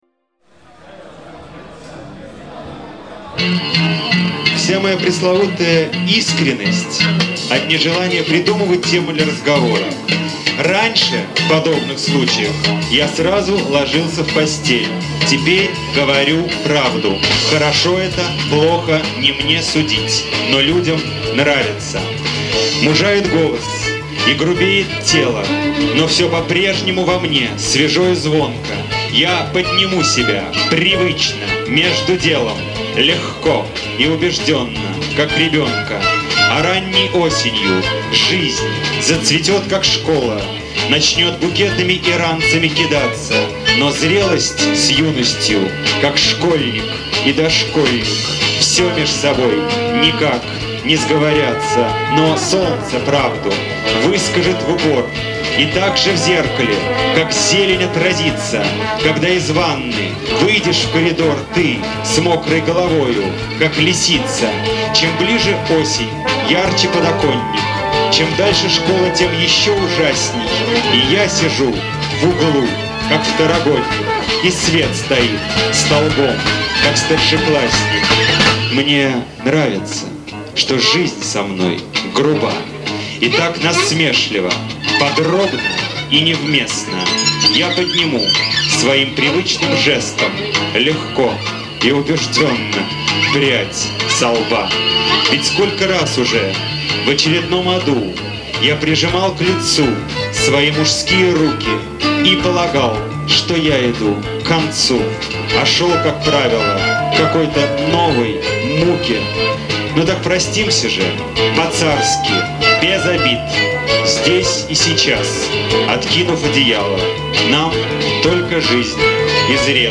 саундтрек к трем книгам
записано в январе-феврале 2003 года